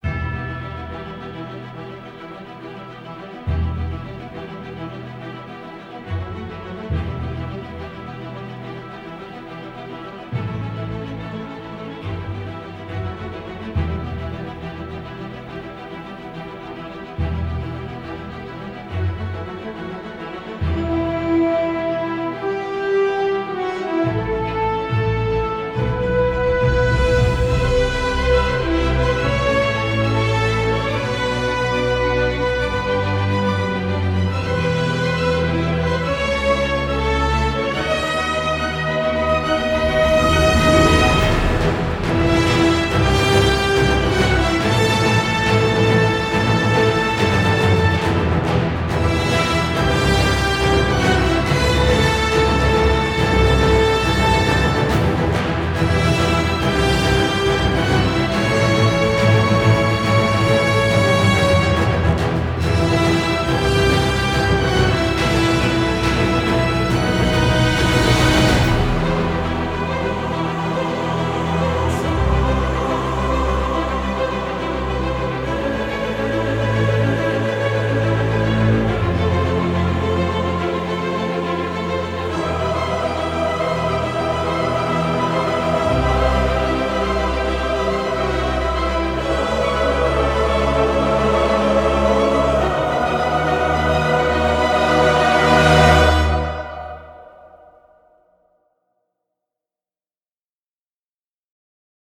عین آهنگای فیلم‌های ماروله